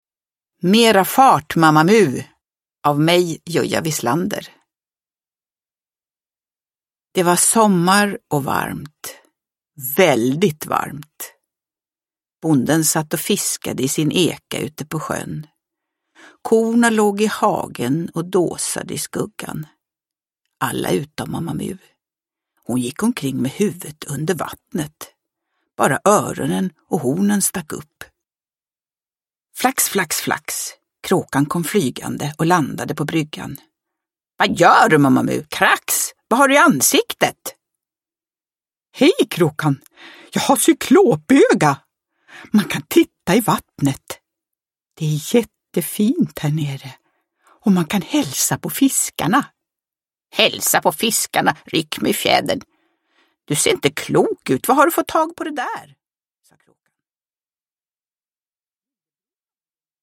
Mera fart Mamma Mu – Ljudbok – Laddas ner
Uppläsare: Jujja Wieslander